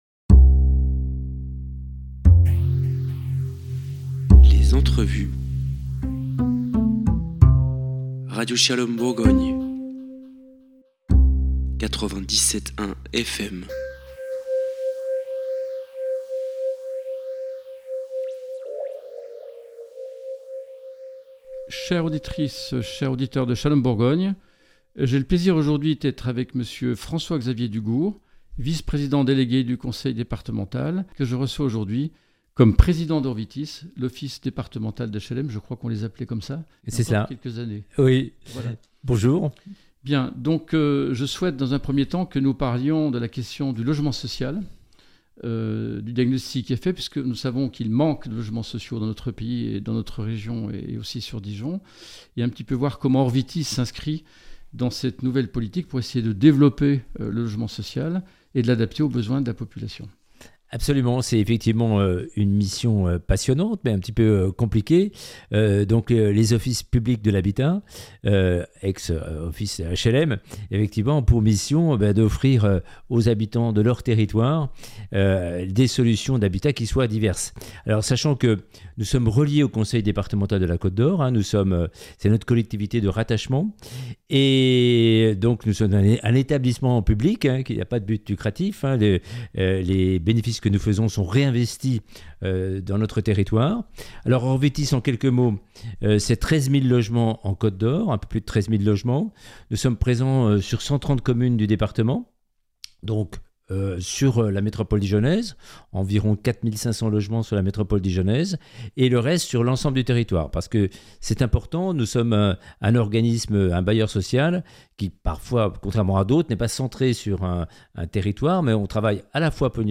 05 août 2025 Écouter le podcast Télécharger le podcast Développer le logement social en Côte d’Or – ORVITIS Shalom Bourgogne reçoit Xavier-François DUGOURD, président d’ORVITIS, l’Office Public de l’Habitat du Conseil Départemental de Côte d’Or.